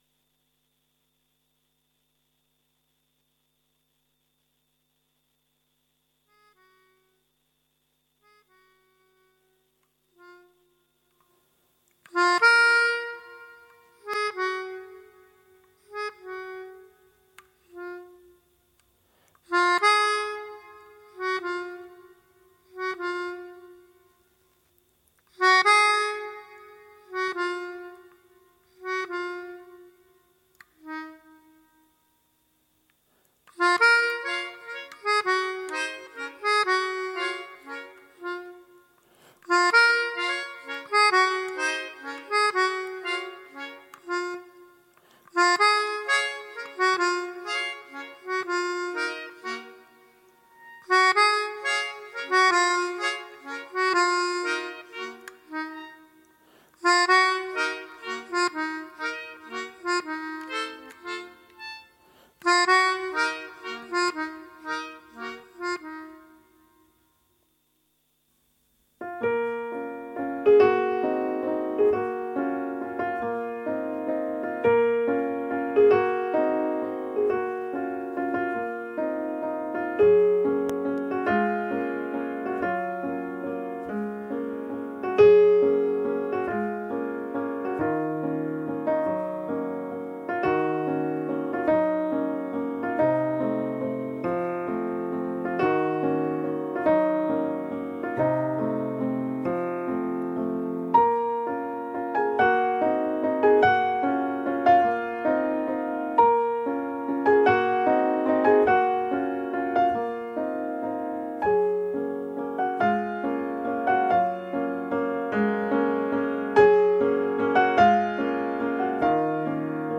French music special